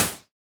Index of /musicradar/retro-drum-machine-samples/Drums Hits/Raw
RDM_Raw_SY1-Snr02.wav